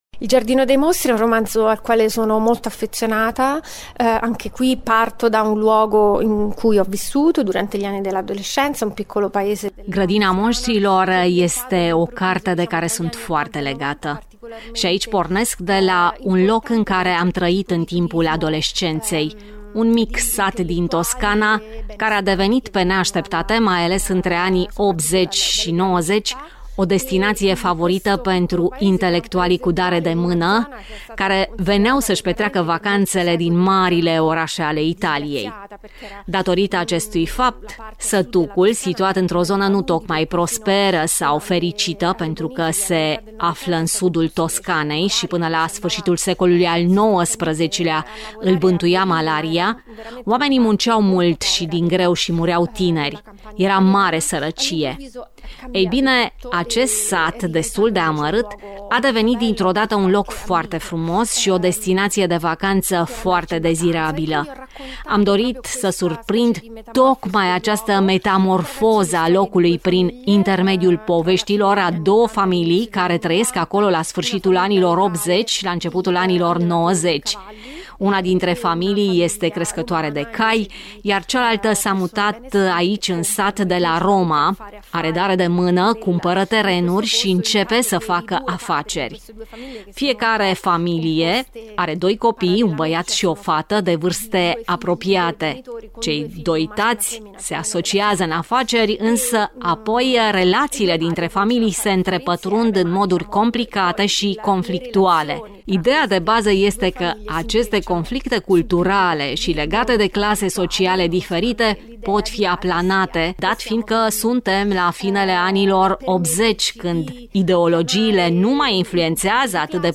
Iată o scurtă recomandare a cărții chiar de la autoarea ei.